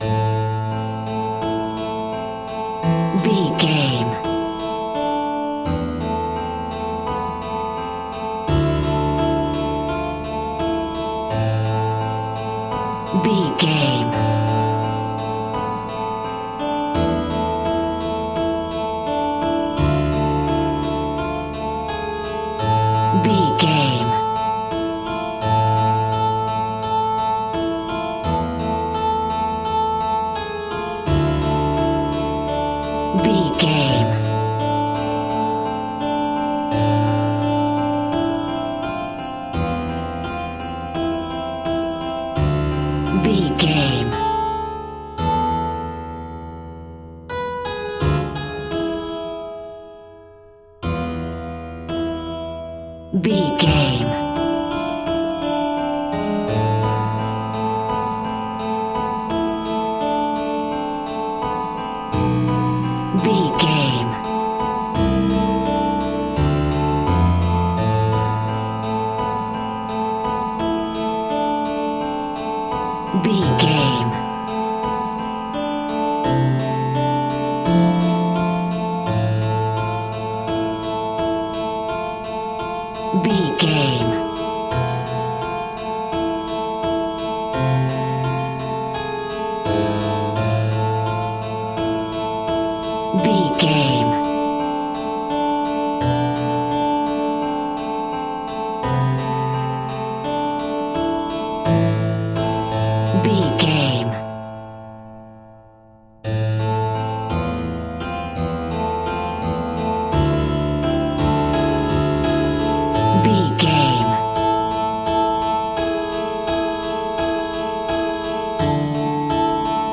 Aeolian/Minor
tension
ominous
dramatic
eerie
flute
synthesiser
harpsichord